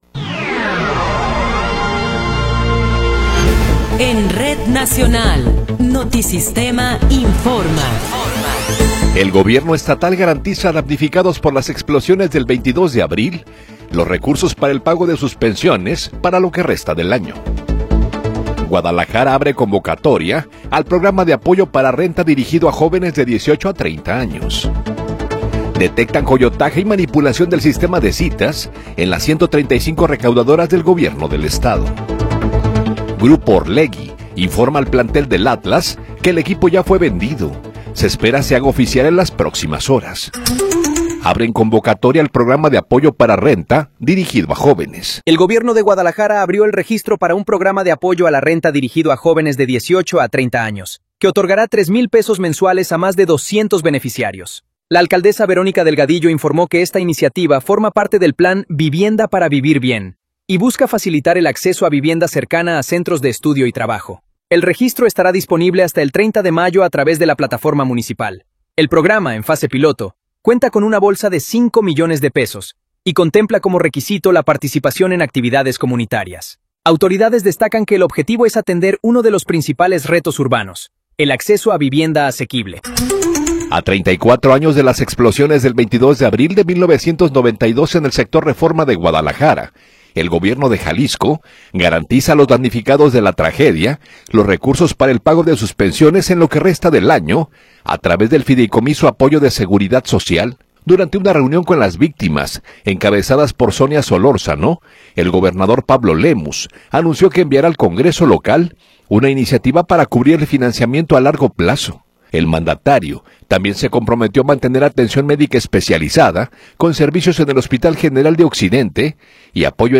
Noticiero 9 hrs. – 22 de Abril de 2026